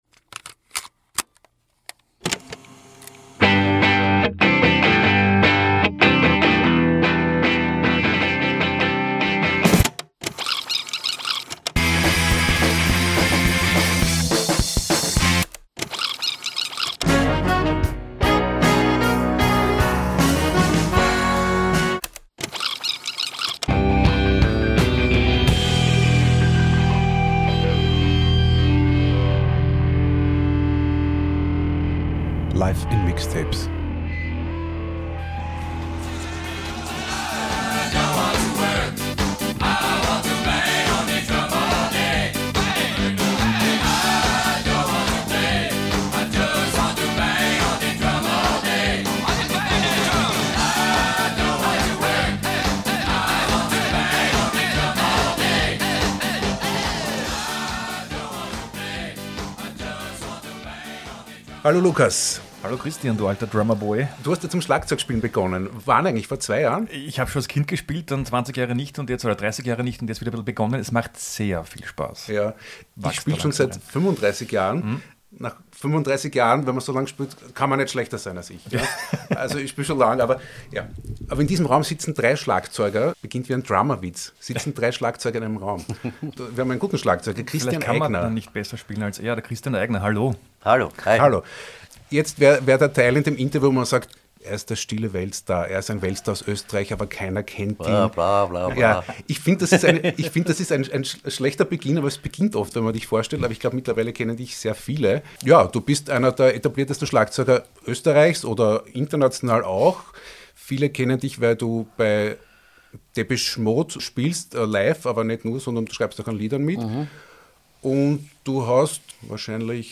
Im heutigen ersten Teil unseres Gesprächs geht es hauptsächlich um den Beginn von Christians Karriere und um Schlagzeug-Legenden, die ihn, uns und Tausende andere Drummer inspiriert und begeistert haben: zB Phil Rudd, Billy Cobham, Jeff Porcaro, Charlie Watts, Taylor Hawkins und Animal aus der Muppet Show.